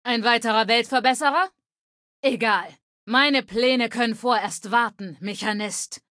Datei:Femaleadult01default ms02 ms02battleflee 000ac040.ogg
Fallout 3: Audiodialoge